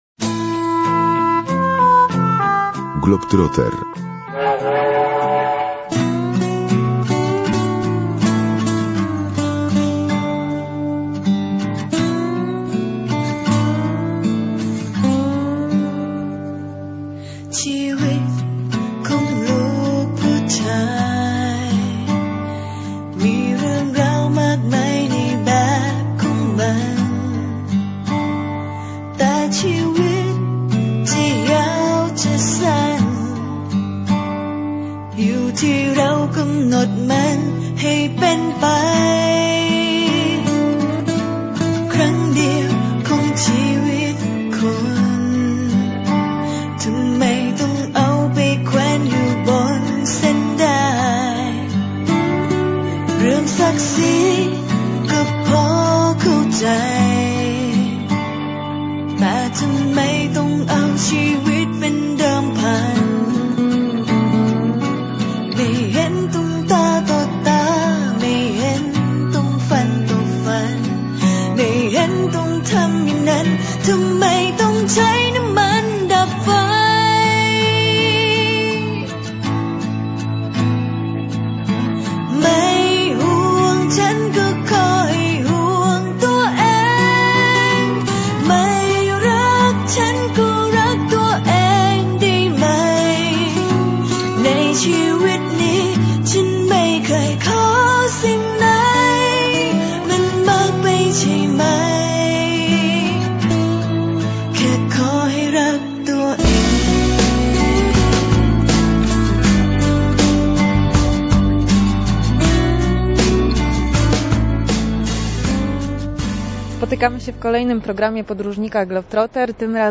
Birma - wywiad w programie Magazyn Podróżnika - Globtroter